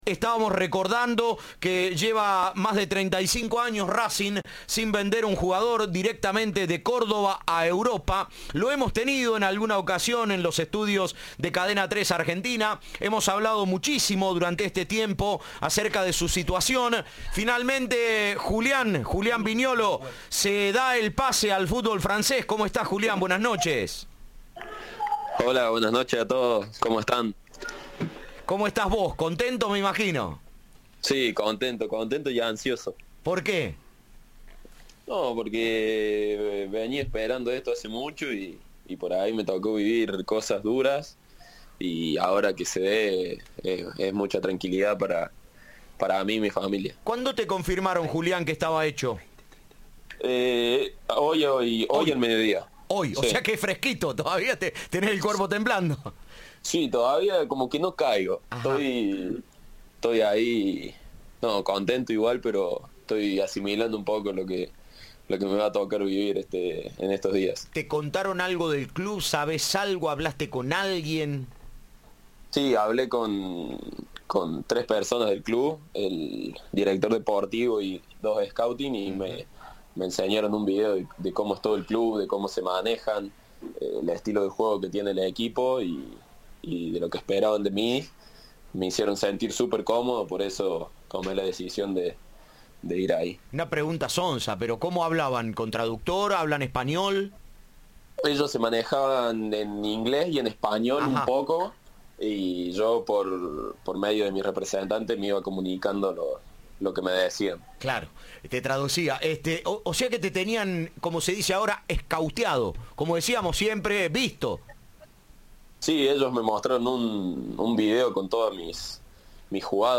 Entrevista de La Cadena del Gol.